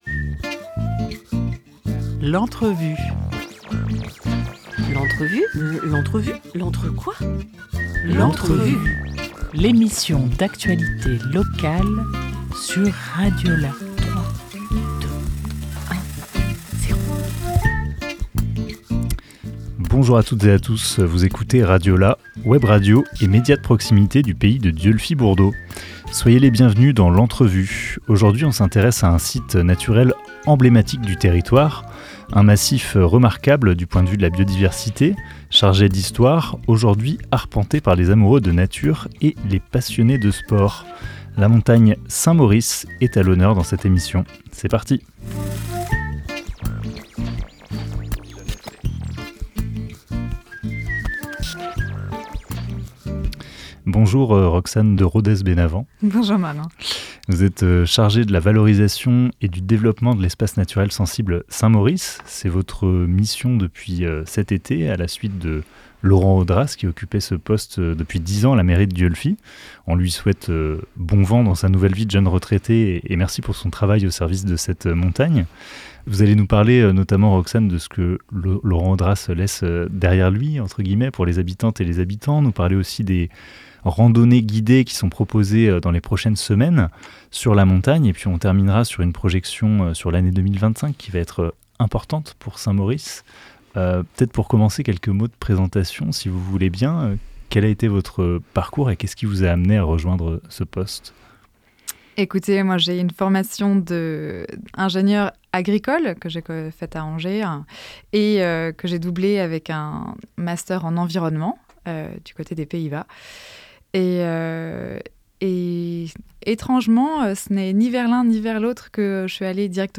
16 septembre 2024 16:20 | Interview